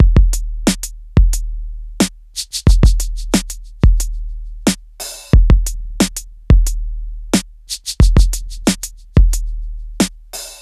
• 90 Bpm Breakbeat D# Key.wav
Free drum loop sample - kick tuned to the D# note. Loudest frequency: 3118Hz
90-bpm-breakbeat-d-sharp-key-caQ.wav